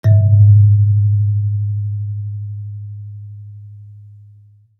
kalimba_bass-G#1-ff.wav